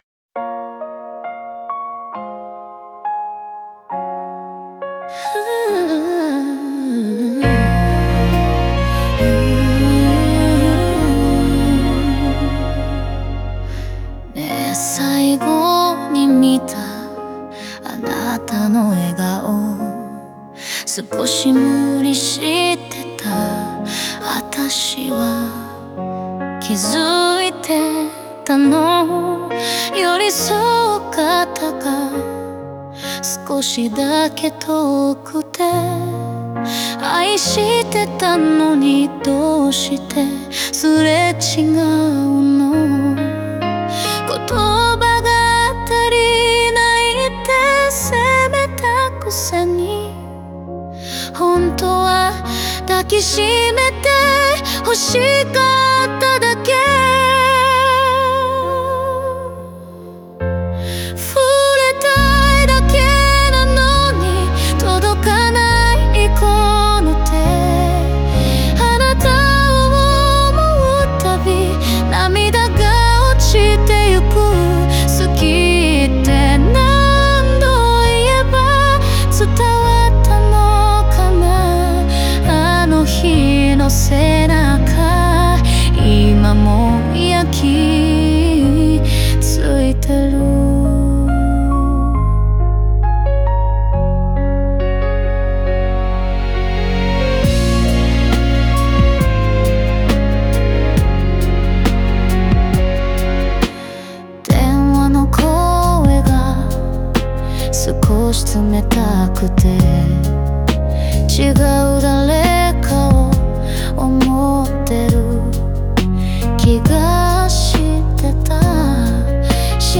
この歌詞は、すれ違いと切なさを描いたバラードです。